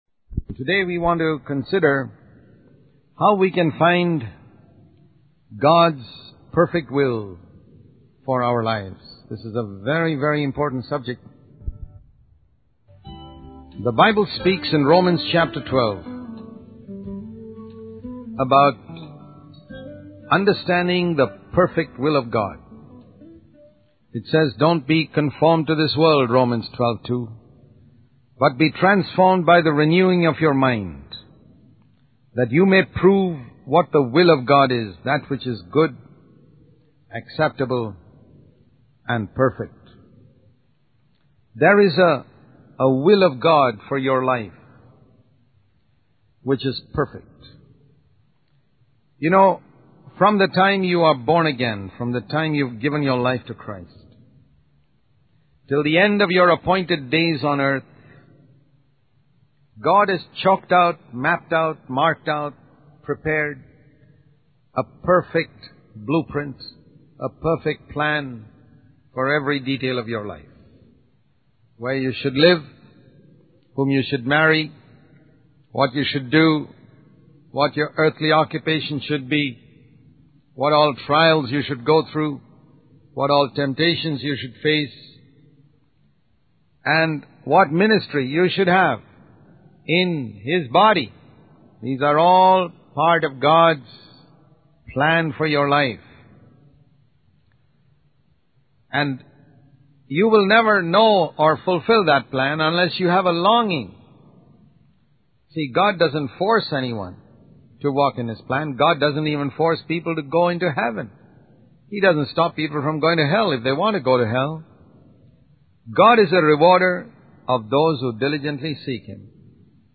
In this sermon, the speaker emphasizes the importance of understanding and seeking God's perfect will for our lives. He explains that God has a detailed plan for every aspect of our lives, including where we live, whom we marry, and our earthly occupation.